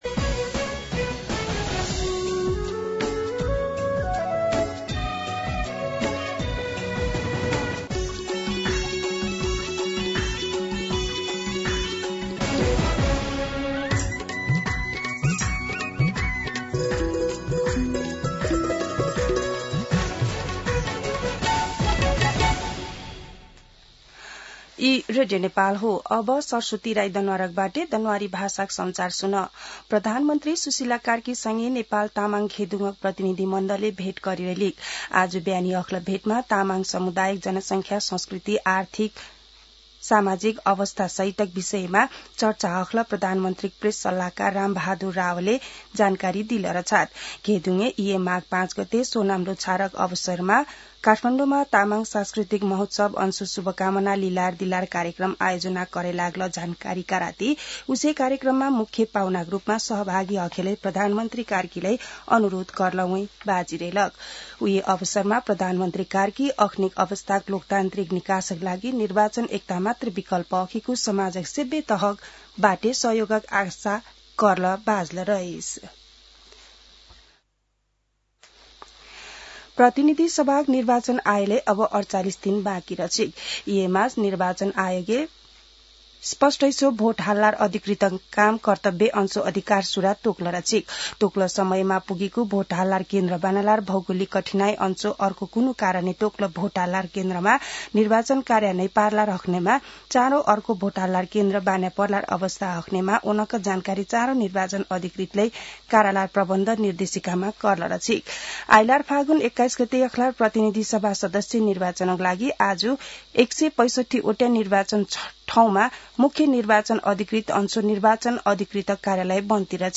दनुवार भाषामा समाचार : २ माघ , २०८२
Danuwar-News-10-2-.mp3